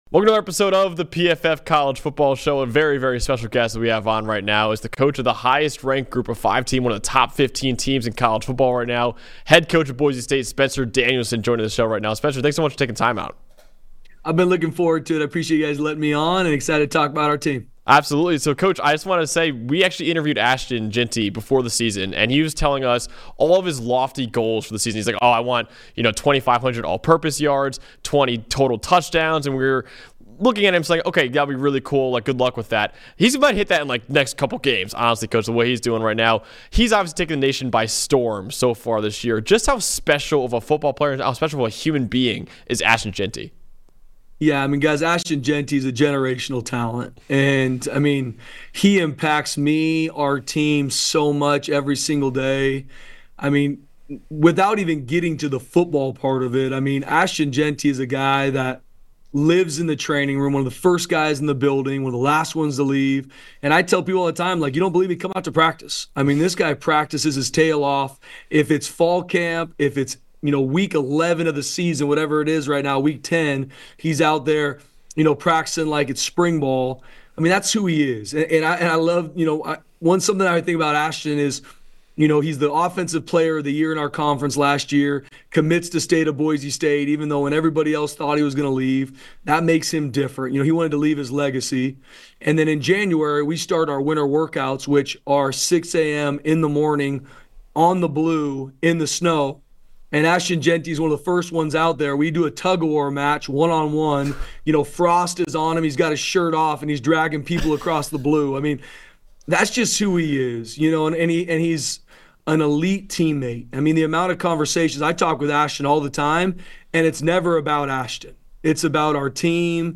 PFF Interview